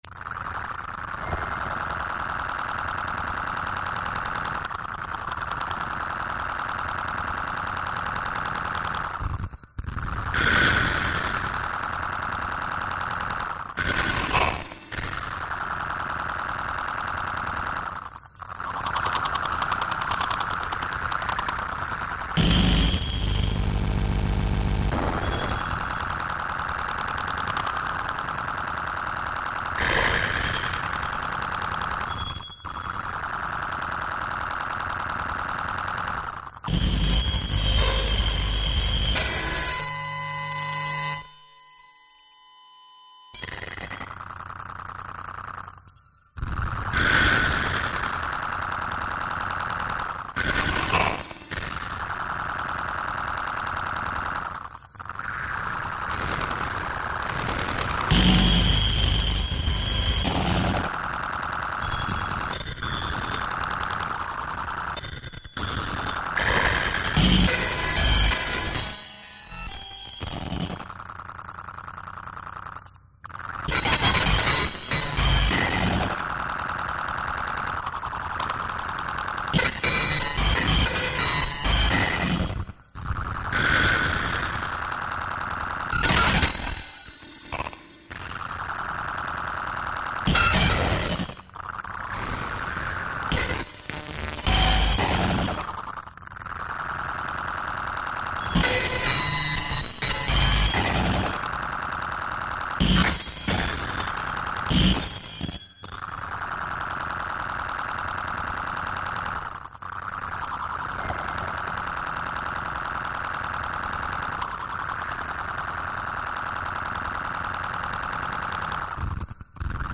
File under: Dada / Power Electronics